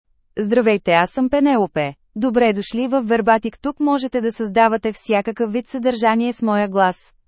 Penelope — Female Bulgarian (Bulgaria) AI Voice | TTS, Voice Cloning & Video | Verbatik AI
PenelopeFemale Bulgarian AI voice
Penelope is a female AI voice for Bulgarian (Bulgaria).
Voice sample
Female